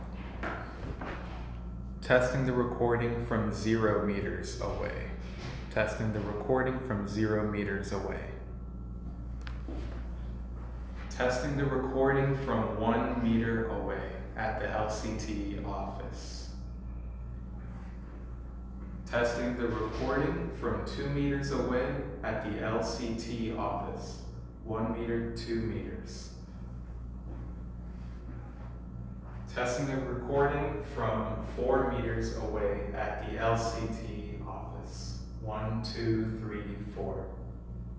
Demo 2 – Small Conference Room
Raw recording (Samsung Galaxy S20):
Audio9_Samsung_S20.wav